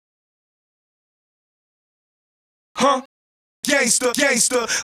Hands Up - Gangsta Vox.wav